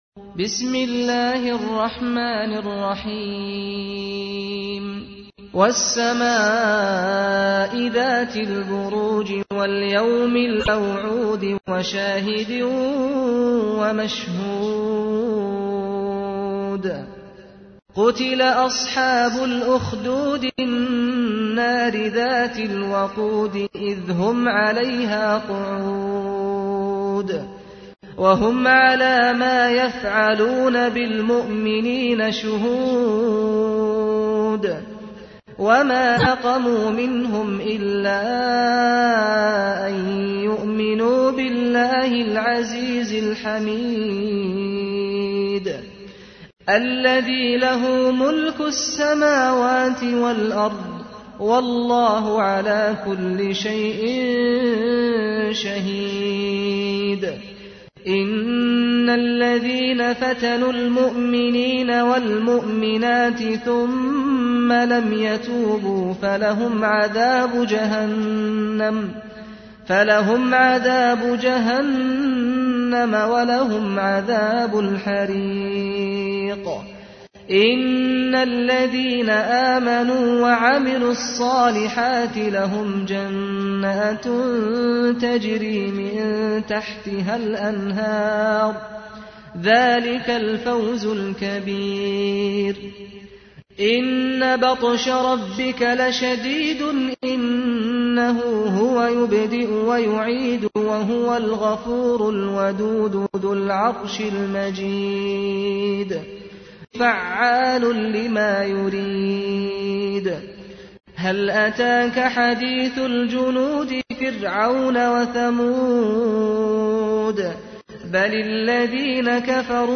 تحميل : 85. سورة البروج / القارئ سعد الغامدي / القرآن الكريم / موقع يا حسين